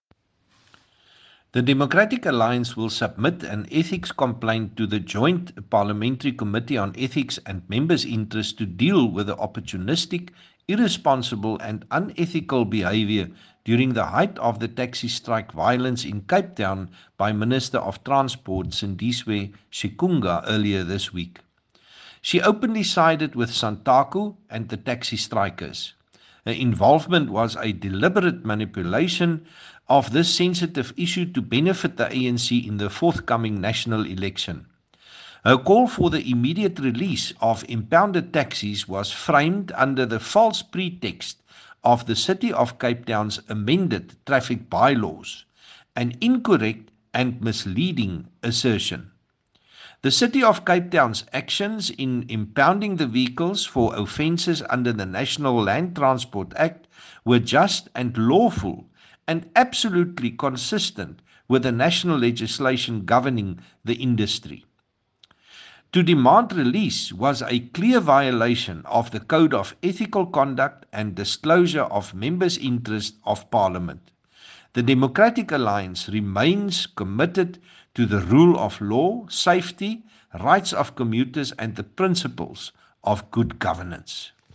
Issued by Dr Chris Hunsinger MP – DA Shadow Minister of Transport
English and Afrikaans soundbites by Dr Chris Hunsinger MP
Dr-Chris-English-sound.mp3